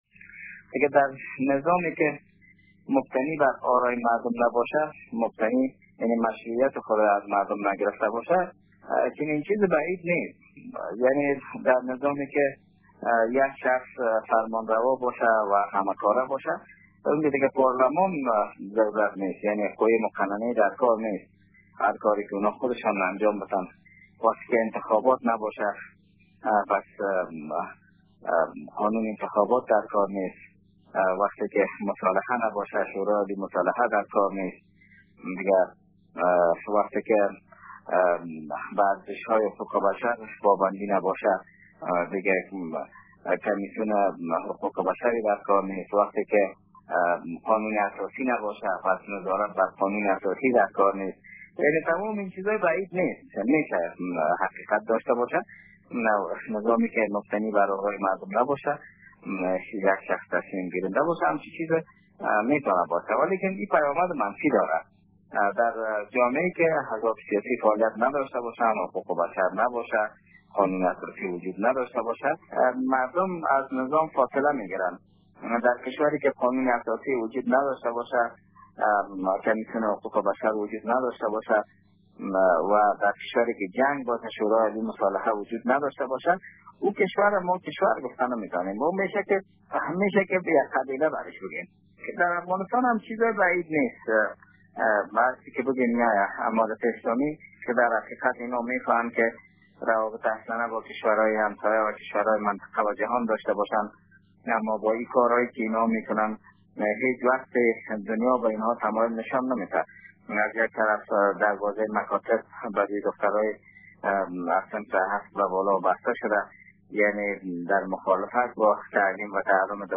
گفت وگو با رادیو دری